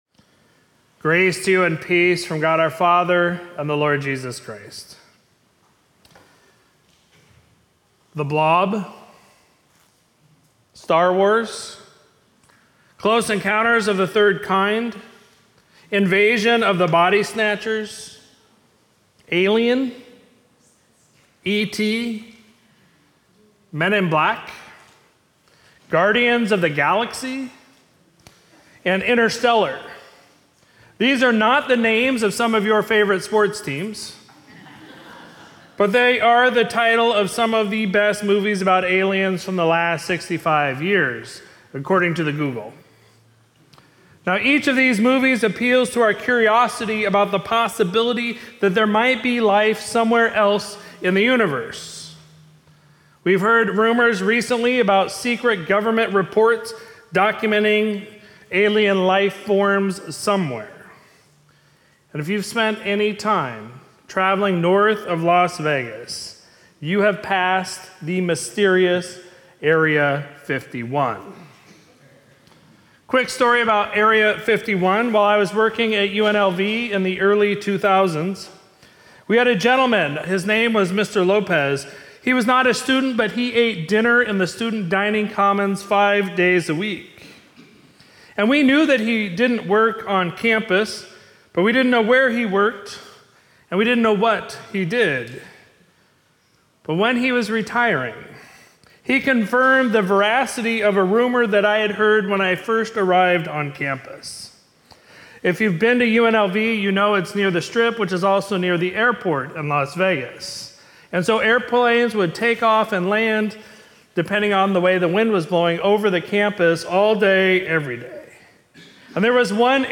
Sermon from Sunday, September 10, 2023